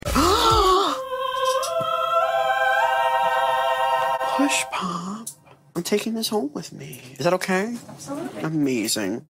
A nicely finished magnetic plate